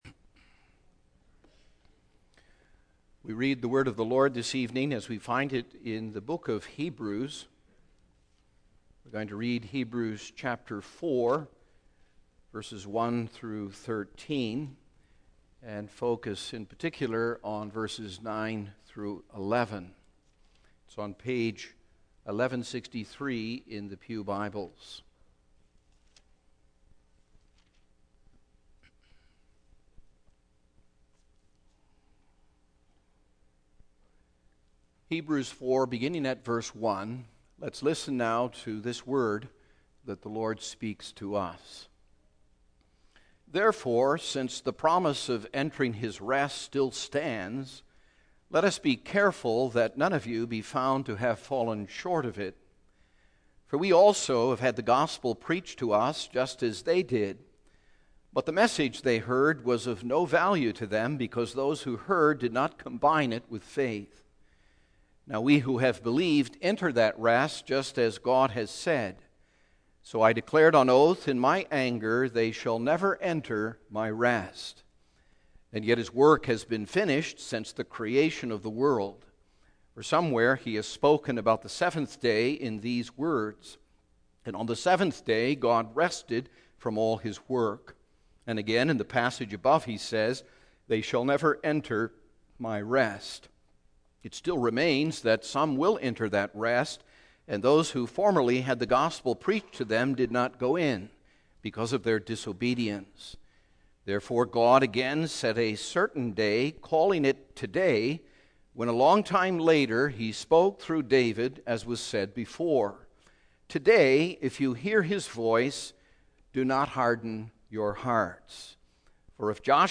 Single Sermons Passage: Hebrews 4:1-13 %todo_render% « Conspiring against Nehemiah What Do You Have That You Have Not Received?